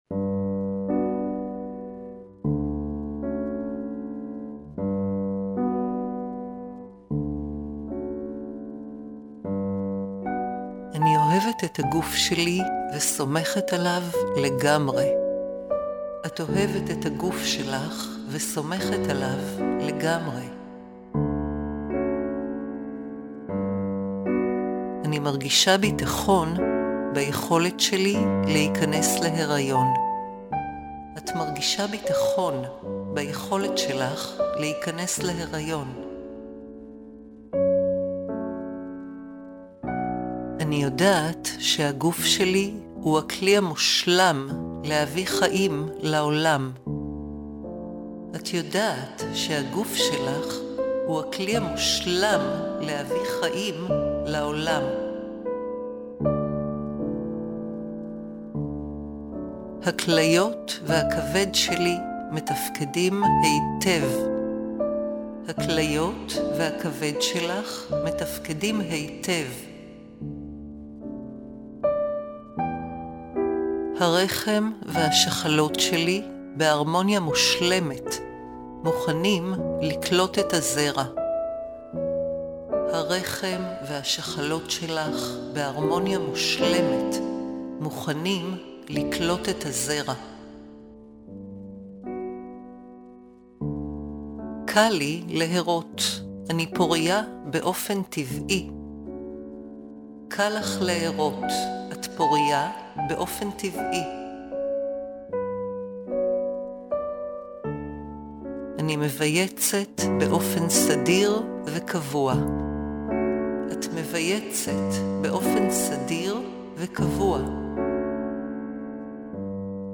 • בנוסף לקלטות הסאבלימינליות, תקבלו גם קובץ של המסרים הגלויים, כפי שהוקלטו במקור בשילוב מוזיקה נעימה.
דוגמה מתוך הצהרות הגלויות לפוריות וכניסה להריון: